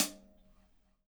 DUBHAT-13.wav